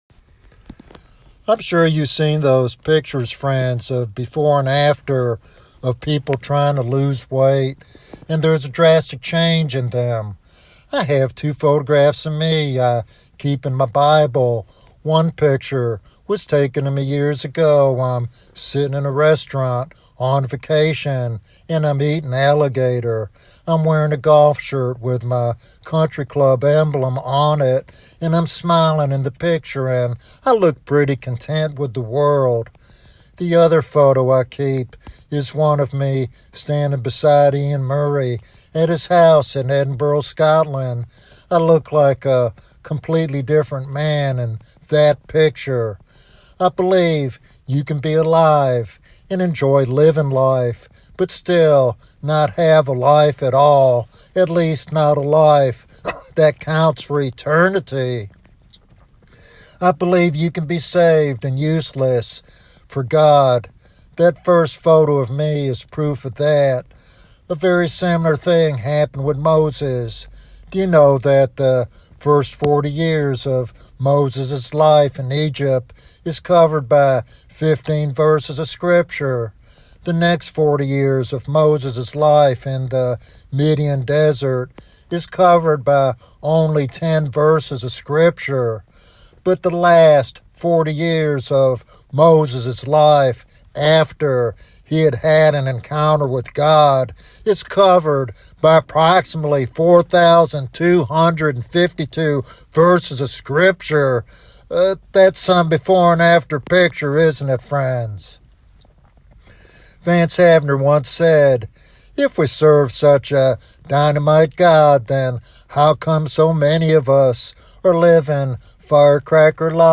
This sermon calls listeners to abandon superficial Christianity and embrace the dynamite power of God that changes lives eternally.